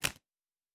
pgs/Assets/Audio/Fantasy Interface Sounds/Cards Place 09.wav at master
Cards Place 09.wav